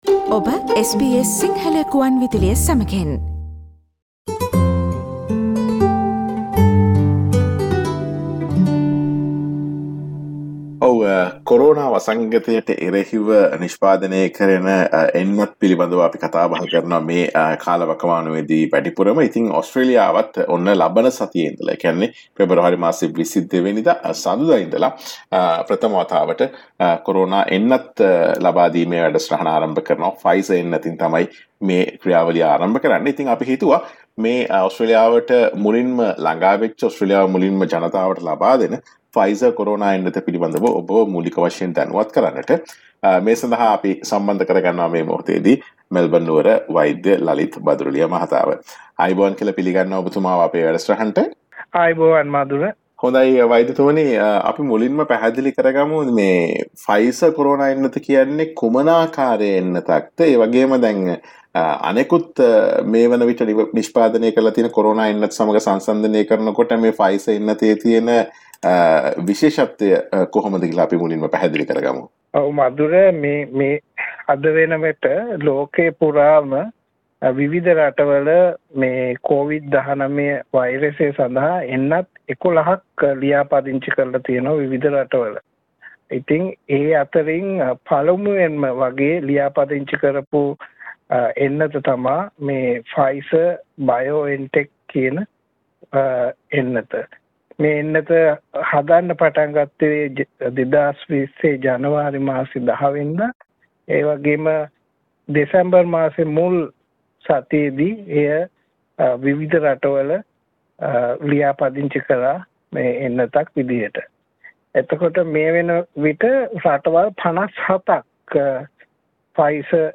SBS Sinhala discusses